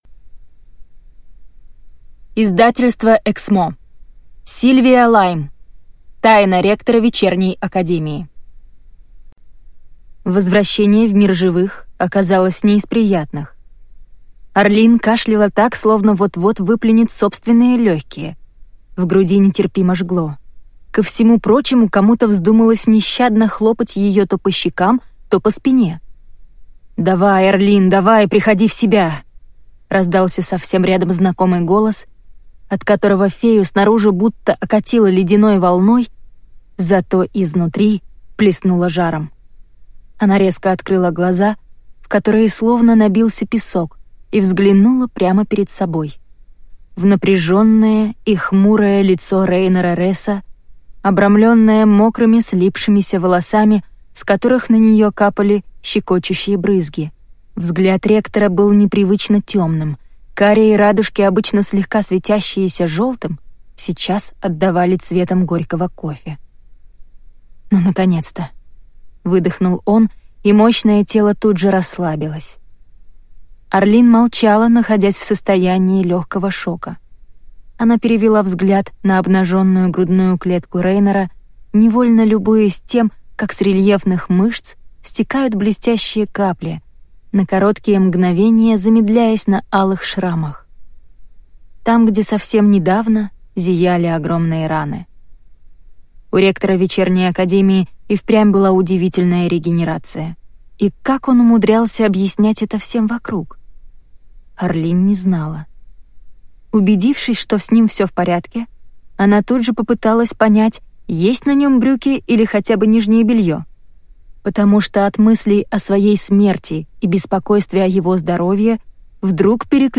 Аудиокнига Тайна ректора Вечерней Академии | Библиотека аудиокниг